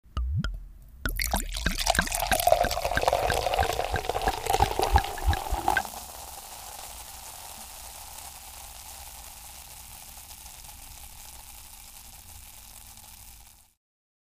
Tuangkan minuman berkarbonat ke dalam gelas, menggosok: